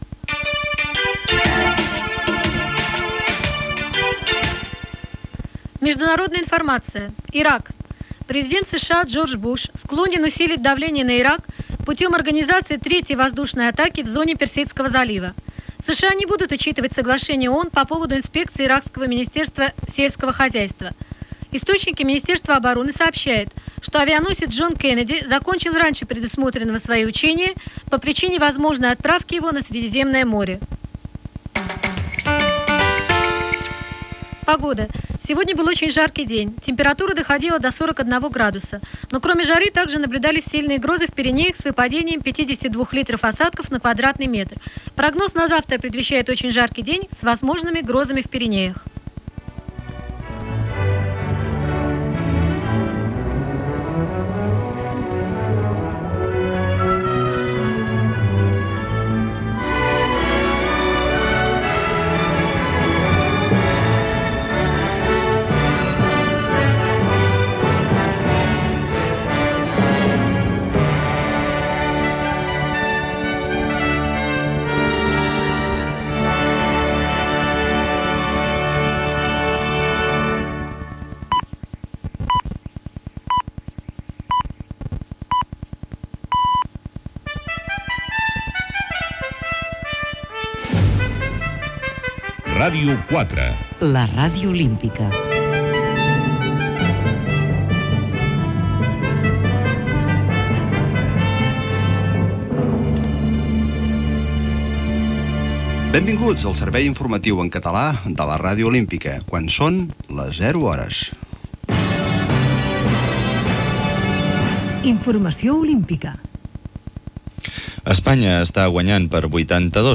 Final de l'informatiu en rus, indicatiu i hora i informatiu en català.
Informatiu
FM